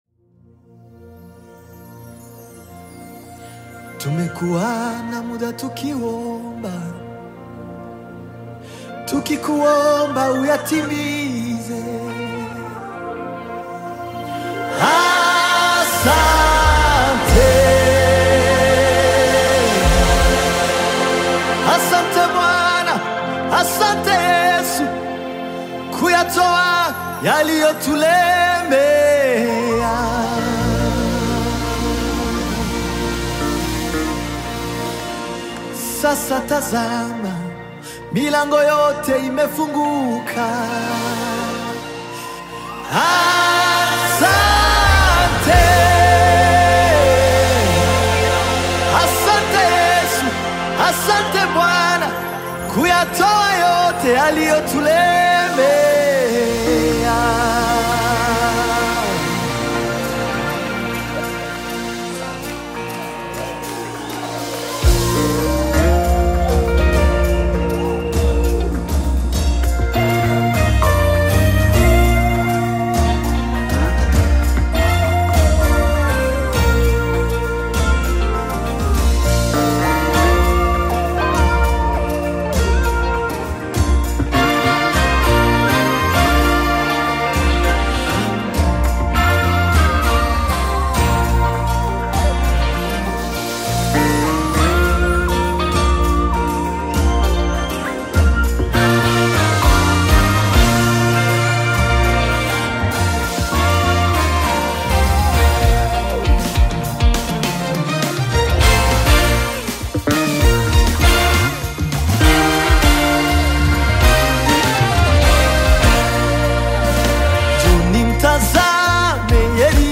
Nyimbo za Dini music
Gospel music track
Tanzanian gospel artist, singer, and songwriter